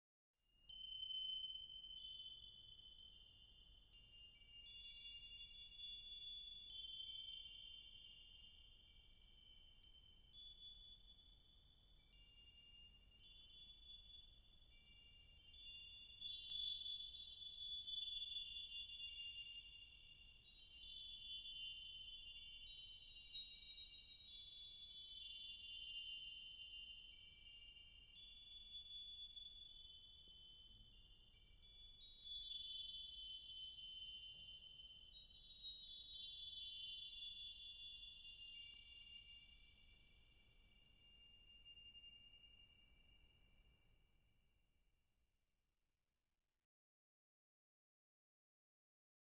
horror
Horror Wind Chimes